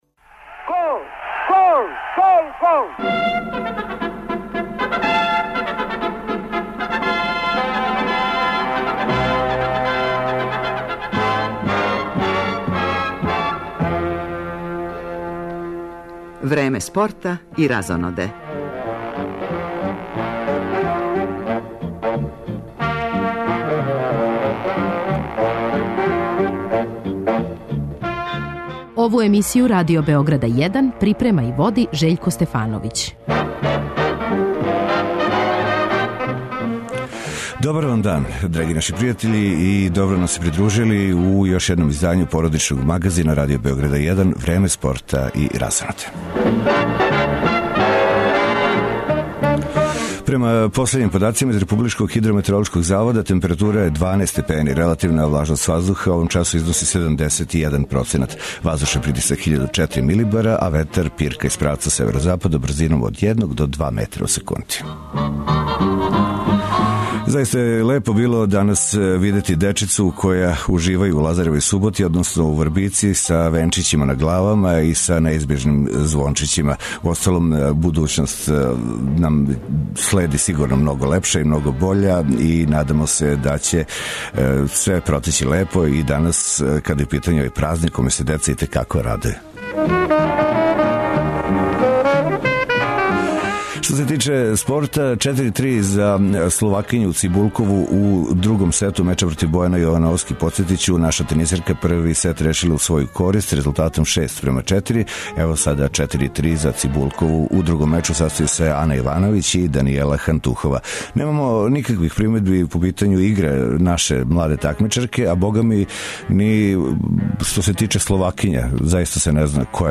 На почетку емисије пратимо завршницу фудбалског меча из Крагујевца између Металца и Црвене Звезде, а потом и комбиноване преносе осталих утакмица Супер лиге Србије, које су на програму у поподневним сатима. Имаћемо и укључења из Спортског центра 'Вождовац' на Бањици, са финалне утакмице Меморијалног турнира РТС-а у малом фудбалу 'Играјмо за 16'.